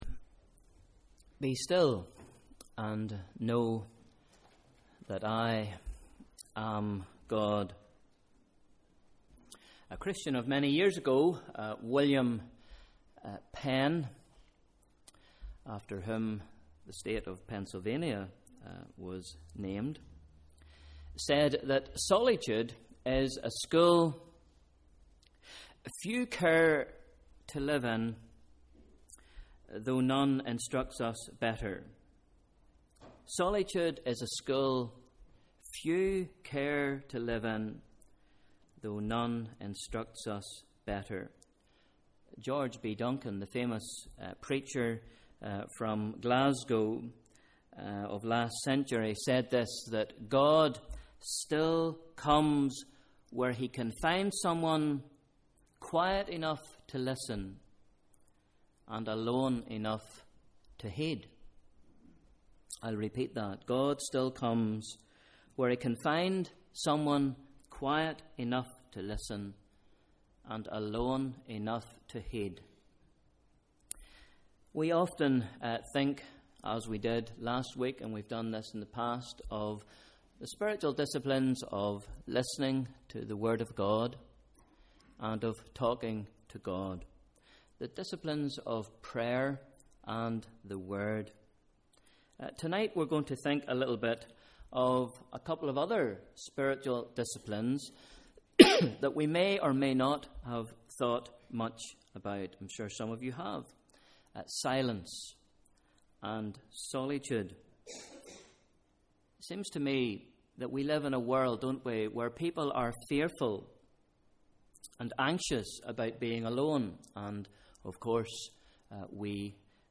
Evening Service: Sunday 29th September 2013 / Bible Reading: Psalm 62